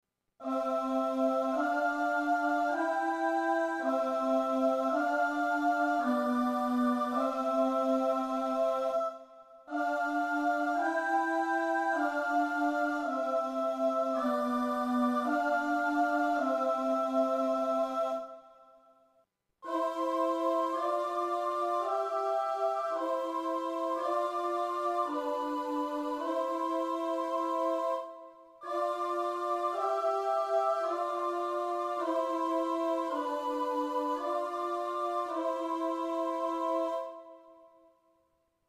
• ammettere, nelle composizioni, anche i nuovi intervalli di terza e di sesta, gradevoli all’ascolto e più facili da intonare (nell'audio): in un primo momento, solo gli intervalli di ottava e di quinta venivano ritenuti "perfetti".
intervalli_terza_sesta.mp3